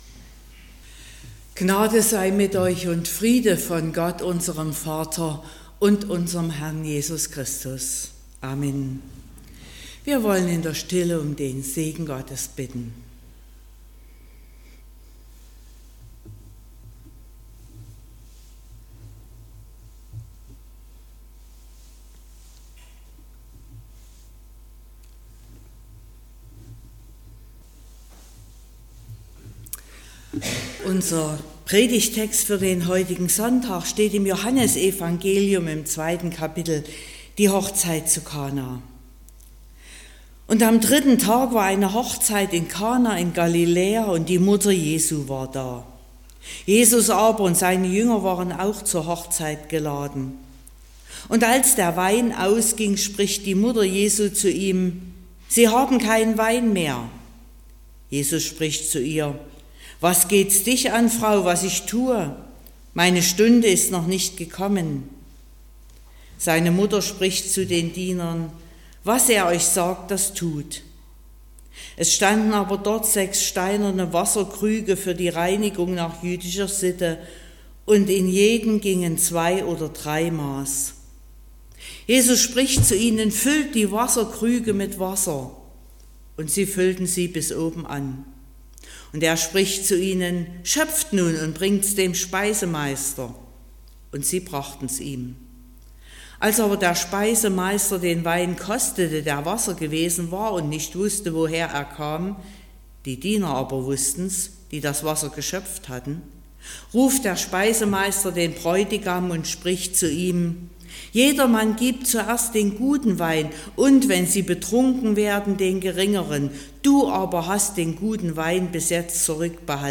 26.01.2025 – Gottesdienst
Predigt und Aufzeichnungen
Predigt (Audio): 2025-01-26_Jesus__wer_bist_du__Die_Hochzeit_zu_Kana__Predigtreihe_2025__Thema_2_.mp3 (16,2 MB)